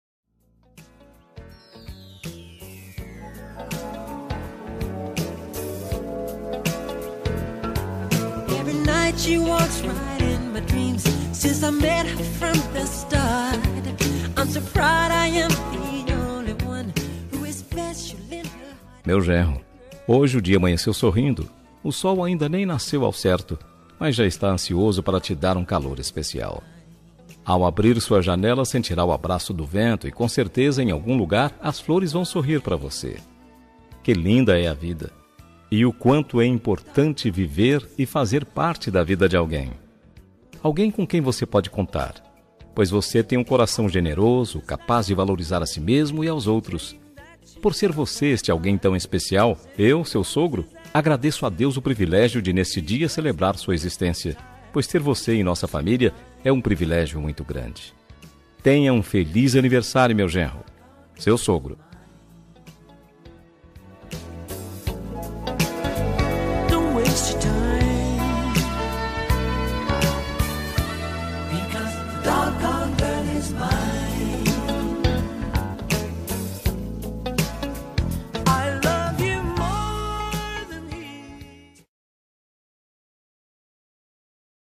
Aniversário de Genro – Voz Masculina – Cód: 348965